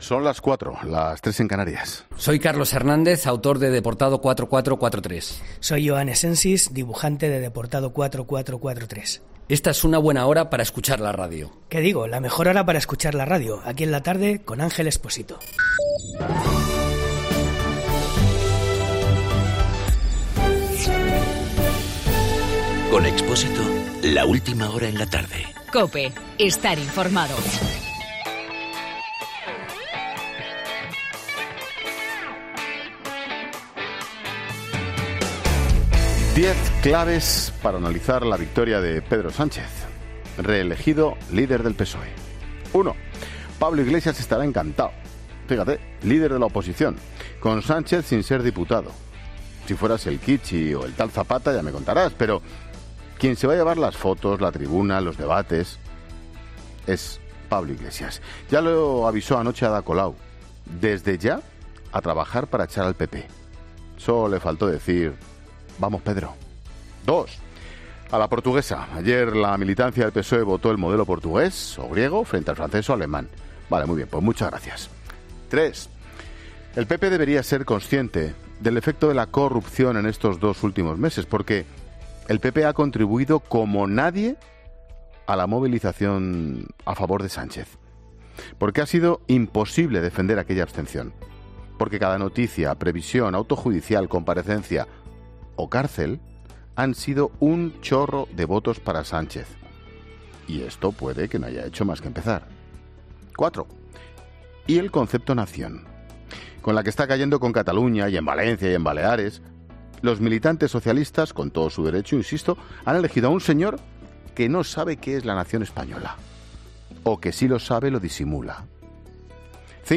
AUDIO: Monólogo 16 h.